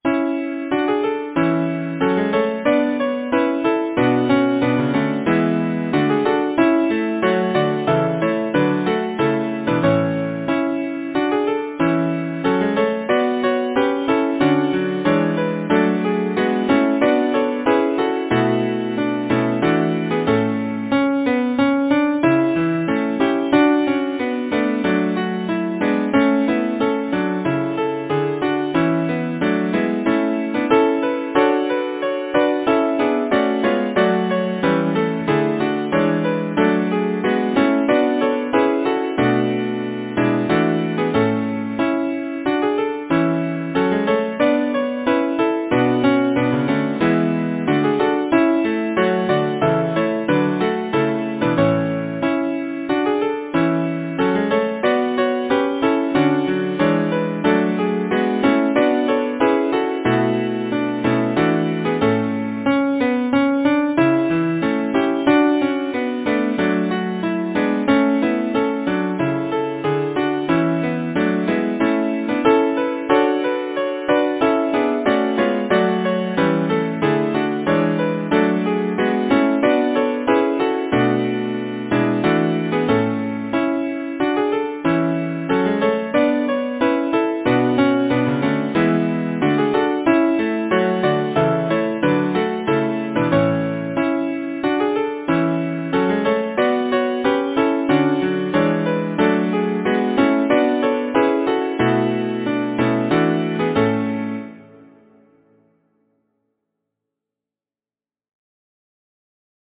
Lyricist: William Hamilton Number of voices: 4vv Voicing: SATB Genre: Secular, Partsong, Folksong
Language: Lowland Scots Instruments: A cappella